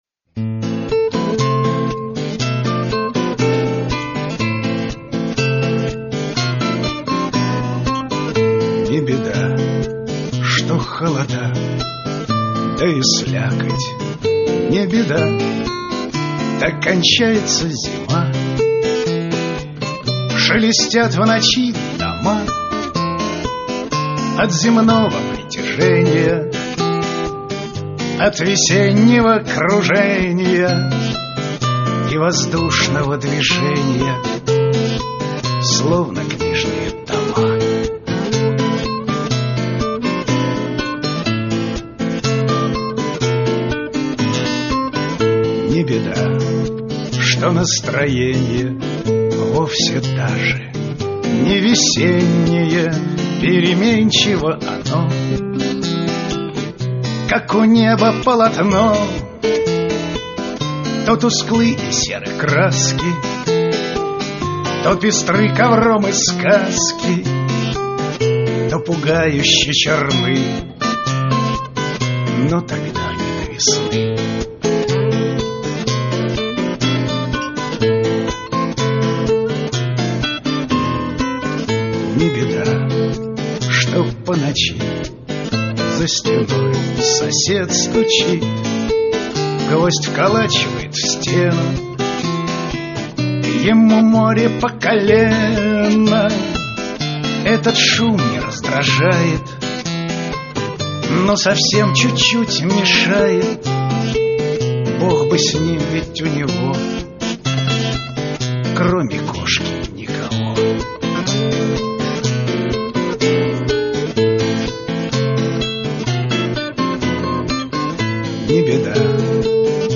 • Жанр: Авторская песня